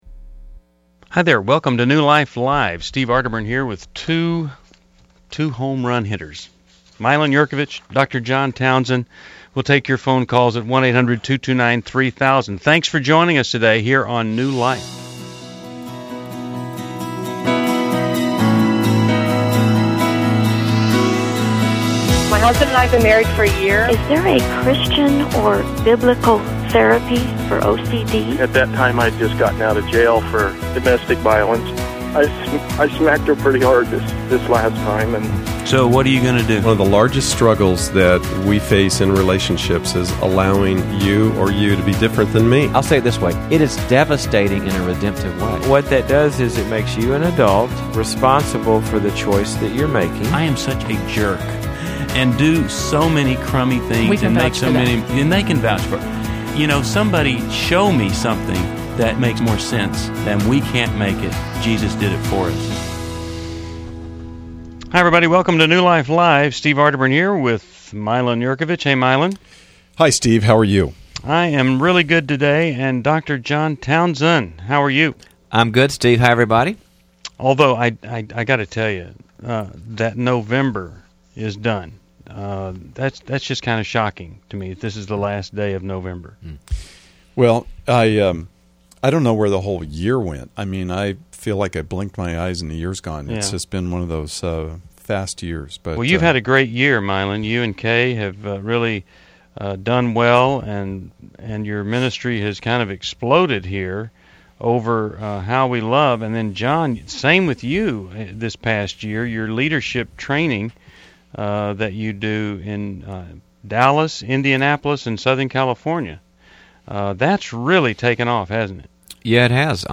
Caller Questions: I wronged a past employer; should I make restitution?